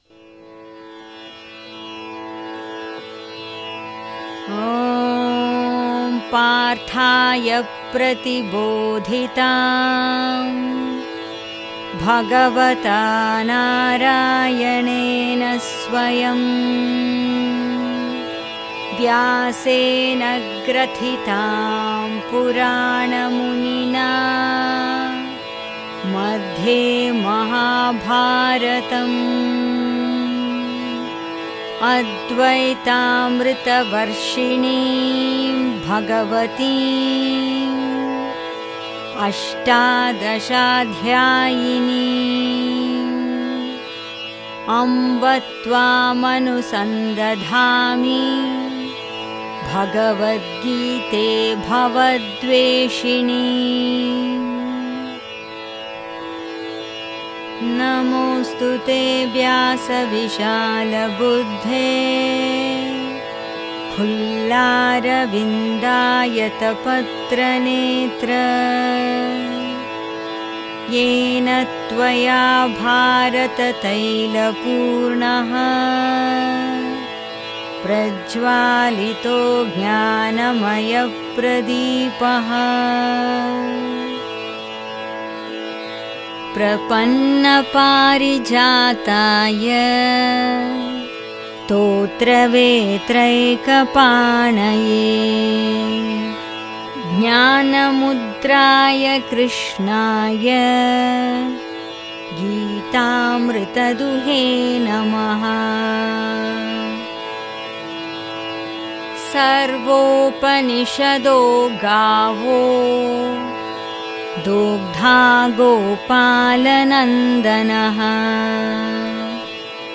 Gita Dhyaana Shlokam (Meditation Verses) Chanting Project
Track 14 – Gita Dhyaana ShlokaaH Together Solo (Duration 5:27)
Gita-Dhyanam-Final-Full-Solo.mp3